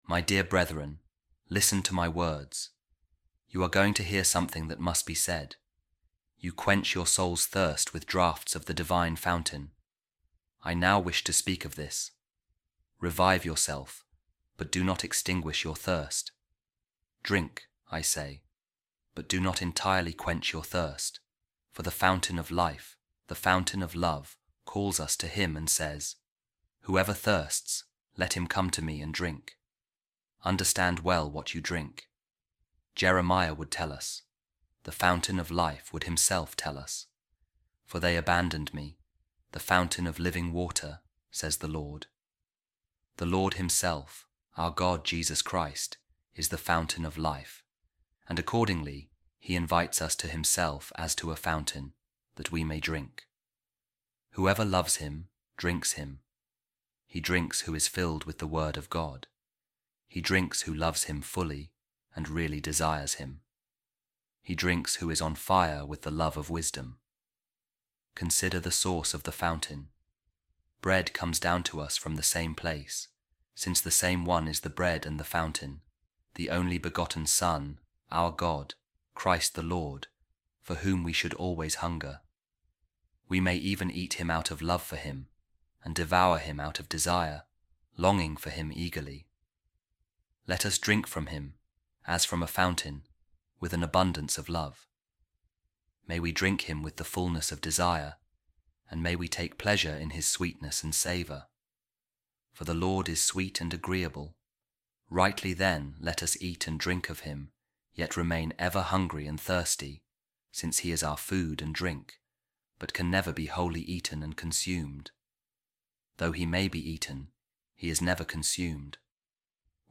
Office Of Readings | Week 21, Wednesday, Ordinary Time | A Reading From The Instructions Of Saint Columban | Taste And See The Goodness Of The Lord